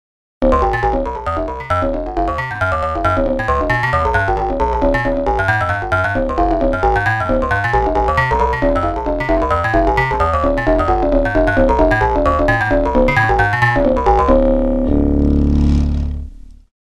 Two examples of a single Jaw Harp sample played quickly with a random 12 steps pitch variation:
JawHarp_1note_rndpitch_02.mp3